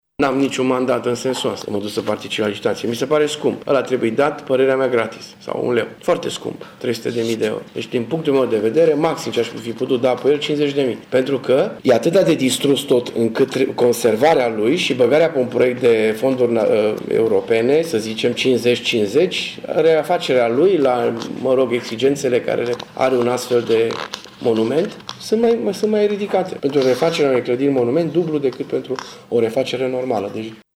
Primarul municipiului Tîrgu-Mureş a declarat azi, într-o conferinţă de presă, că nu are mandat din partea Consiliului Local pentru a participa la licitaţie.
Dorin Florea spune că preţul este mult prea ridicat şi că trebuie să se ţină cont de faptul că reabilitarea unui monument istoric presupune costuri foarte mari: